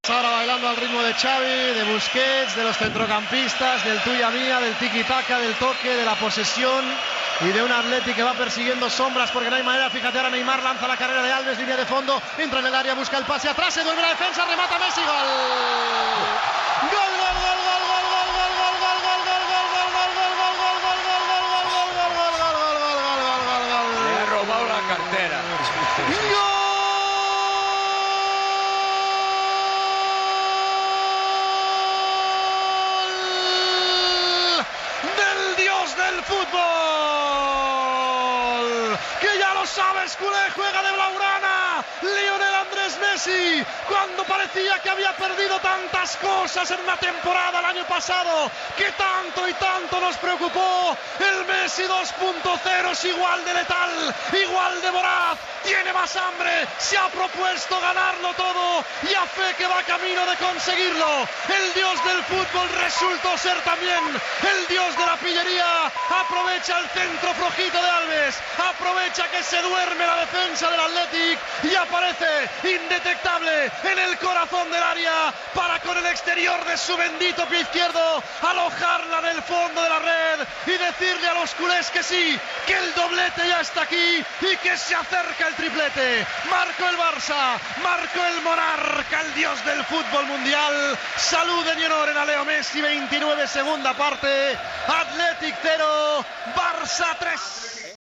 Transmissió del partit de la Copa del Rei de futbol masculí entre l'Athletic Club i el Futbol Club Barcelona.
Narració i lloa del gol de Leo Messi.
Esportiu